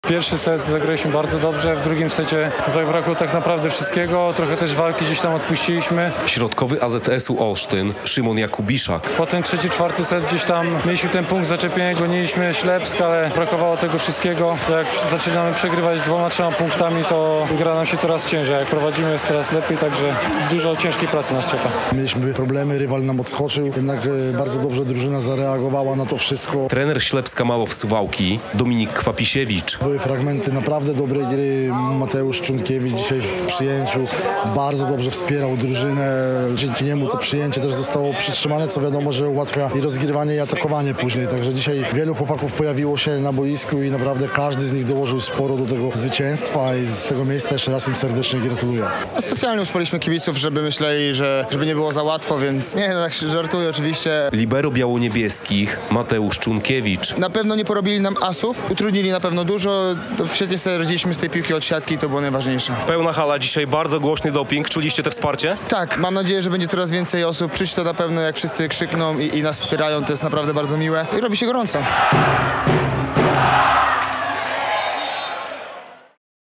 W pierwszym meczu przed własną publicznością w tym sezonie, Ślepsk Malow Suwałki pokonał AZS Olsztyn 3:1 - relacja